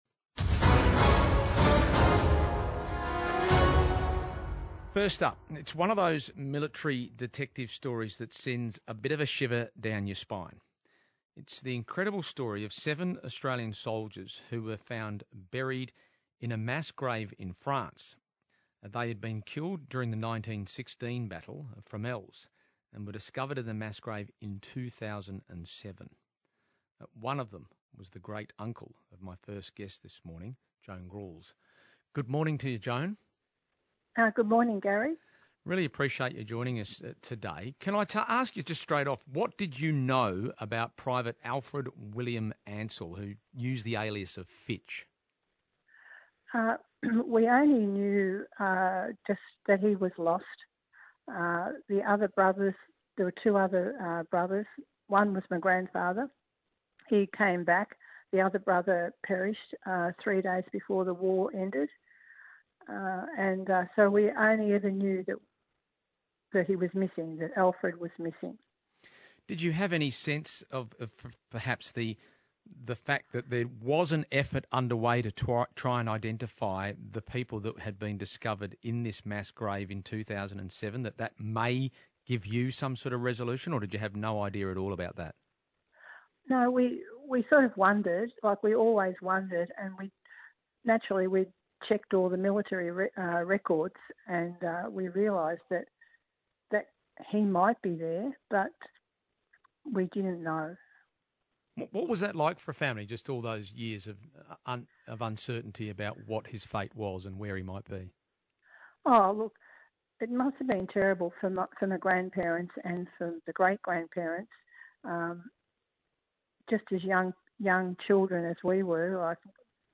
Anzac Day Interview